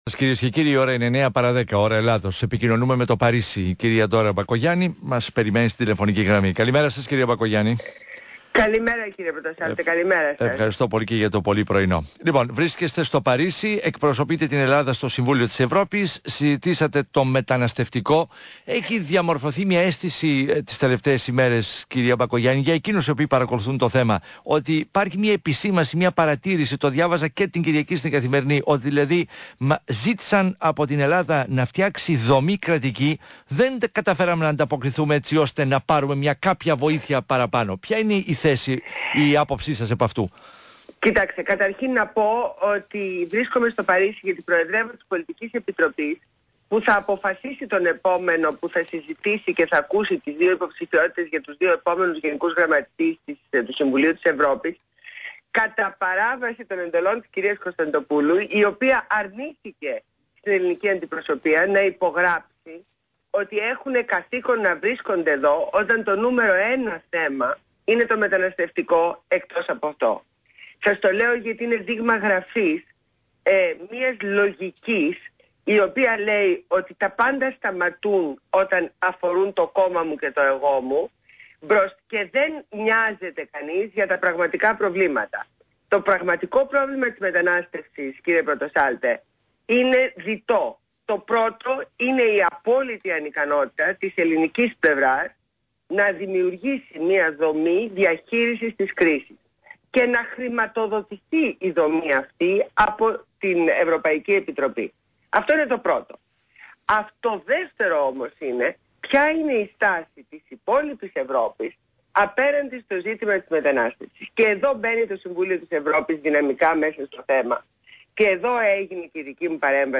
Συνέντευξη στον ραδιοφωνικό σταθμό ΣΚΑΪ 100,3 και το δημοσιογράφο Ά. Πορτοσάλτε
H βουλευτής της ΝΔ Ντόρα Μπακογιάννη παραχώρησε συνέντευξη στον ραδιοφωνικό σταθμό ΣΚΑΪ 100,3 και το δημοσιογράφο Άρη Πορτοσάλτε.